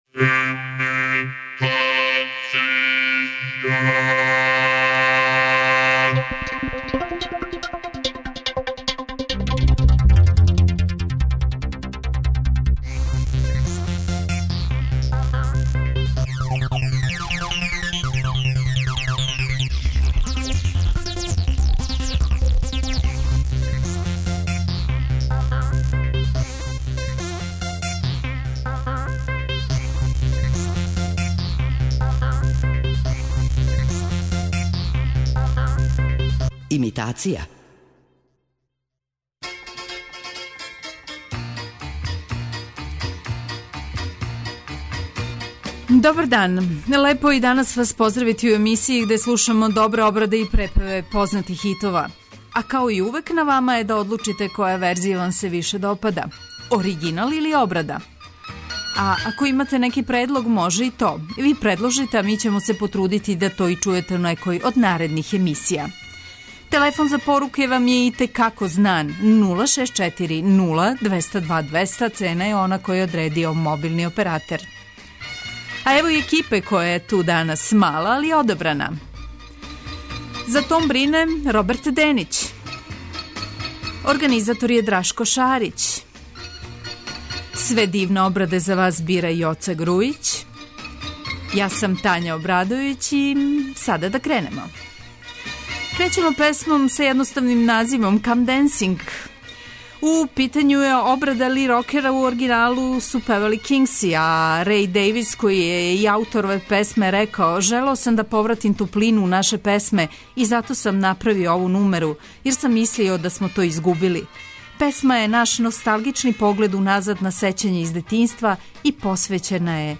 преузми : 26.97 MB Имитација Autor: Београд 202 Имитација је емисија у којој се емитују обраде познатих хитова домаће и иностране музике.